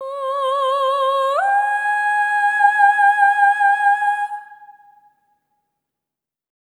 SOP5TH C#5-R.wav